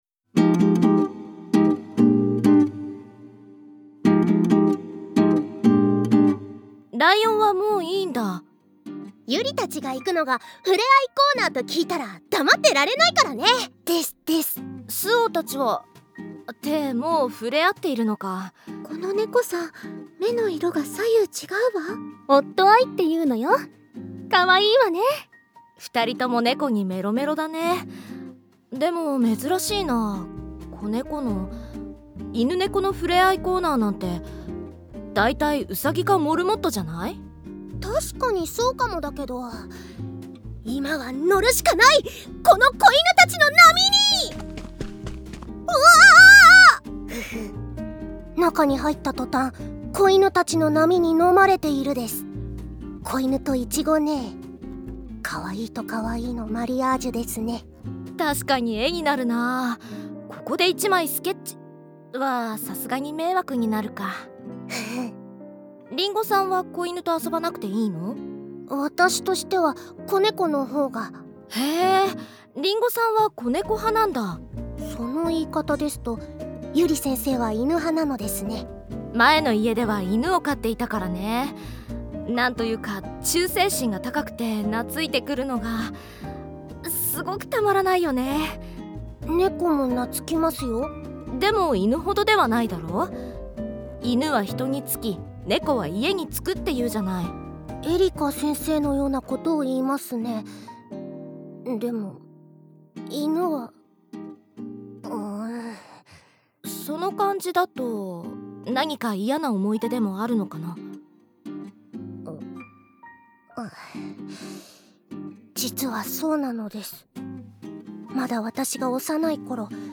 サンプルボイス1